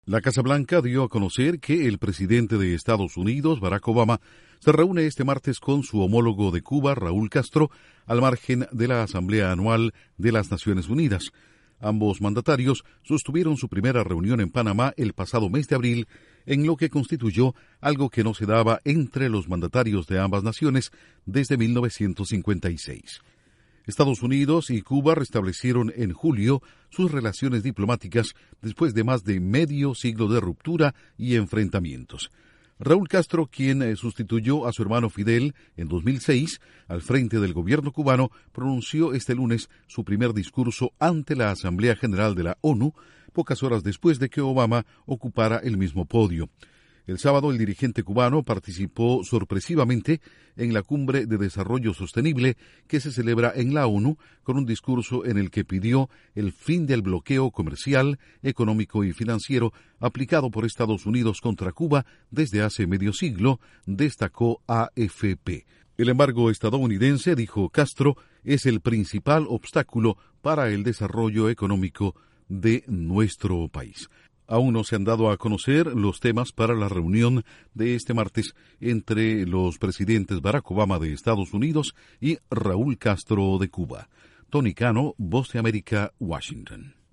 Los presidentes de Estados Unidos y Cuba se reunirán este martes al margen de las Asamblea General de la ONU en Nueva York. Informa desde la Voz de América